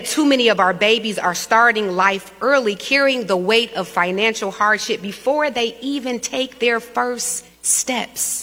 Detroit Mayor Mary Sheffield said last week the Rx Kids program is vital.